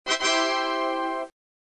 Вот это идея — тада